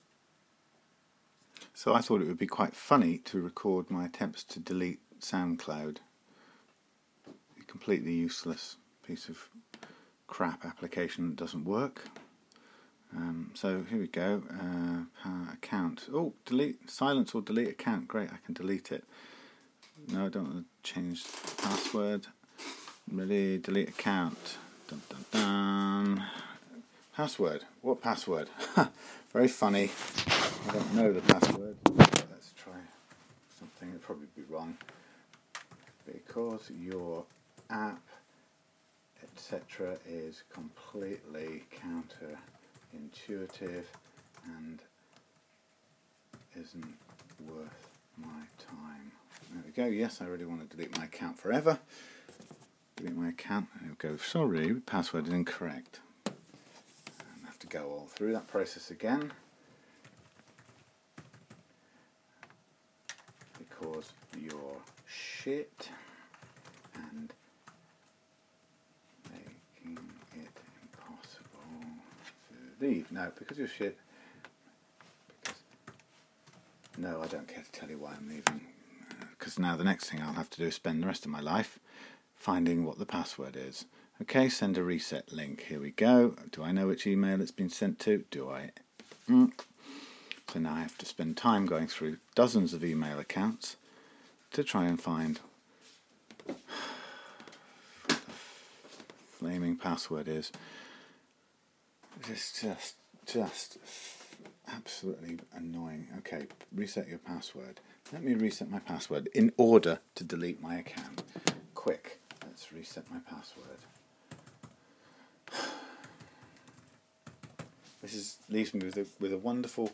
SoundCloud is so unintuitive that it has driven me mad since I first wasted my time trying to use it. Listen to my rising frustration as I go through the hassle of deleting my account.